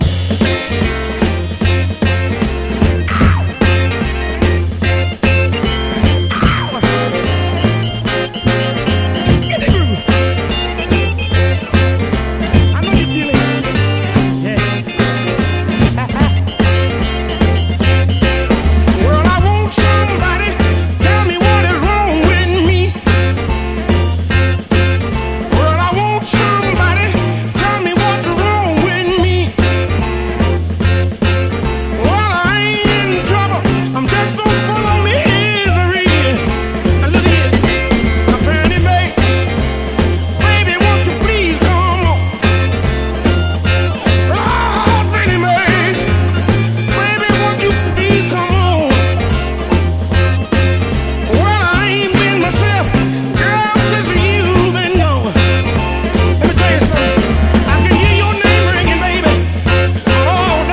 痛快フィメール・ノーザンソウル！ ハスキーな声にグッと来ます。